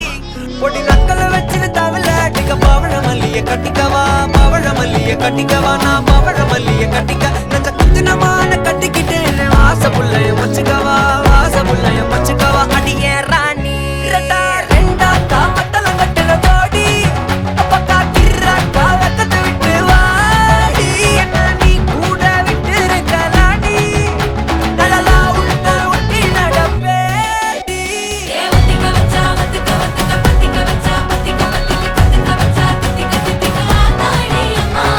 best flute ringtone download | love song ringtone
romantic song ringtone download